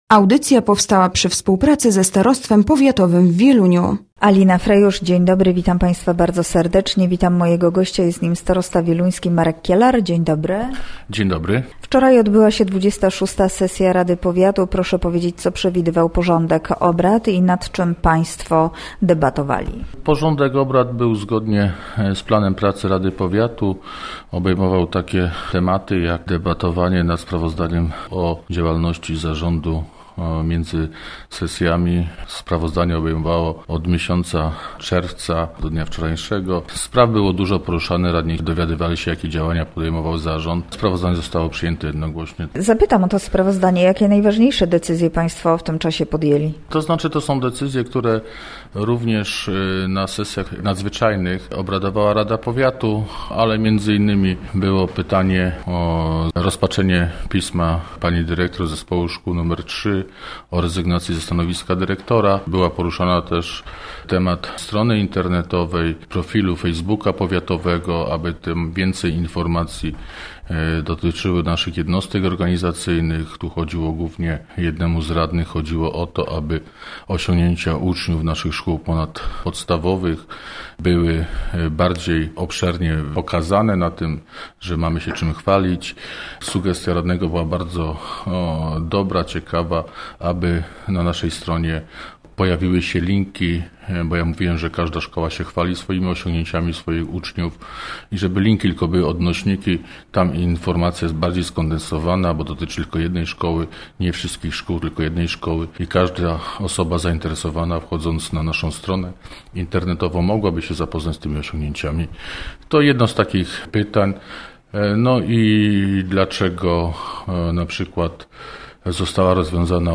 Gościem Radia ZW był Marek Kieler, starosta wieluński